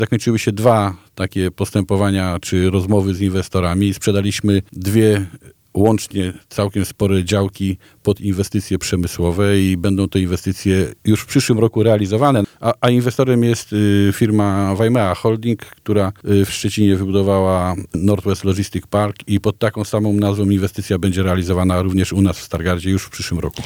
Nowym inwestorem, który kupił znaczną część działek w parku przemysłowym jest firma Waimea Holding – mówił na naszej antenie prezydent Stargardu Sławomir Pajor.